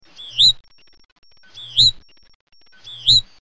Hutton's Vireo (Vireo huttoni)
i. Song: double-noted zu-weep, with rising inflection, sometimes continuously repeated; vireo quality (P).
A ventriloquial chu-weem, chu-weem or zu-weep, zu-weep (L).
Slow song: